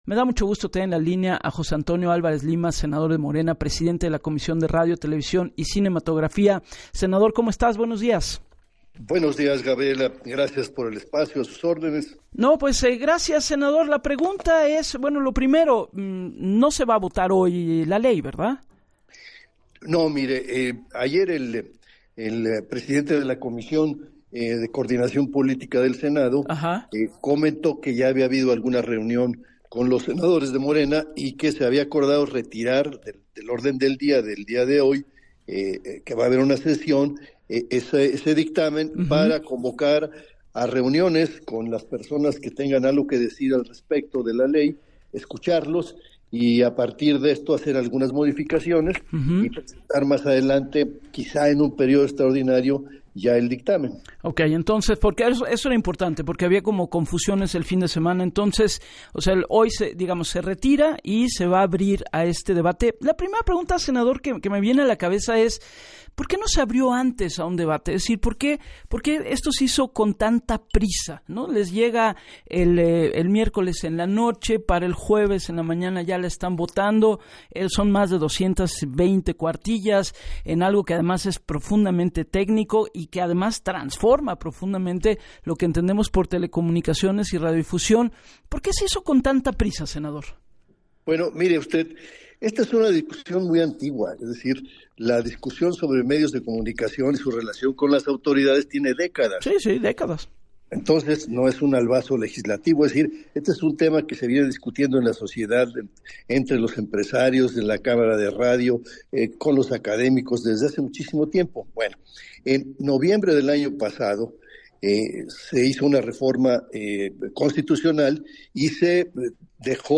En entrevista para “Así las Cosas” con Gabriela Warkentin, el senador morenista, recalcó que tras la desaparición del IFT, órgano regulador, por mandato oficial les fue encargada una Ley de Telecomunicaciones, la que aseguró “se ha discutido en diferentes foros” y resaltó que el día de su aprobación fue tras una larga sesión en la que aseguró “se escucharon todas las voces de todos los partidos políticos... se votó en comisiones y se ganó por mayoría, 29 a favor y 9 en contra”, justificó.